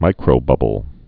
(mīkrō-bŭbəl)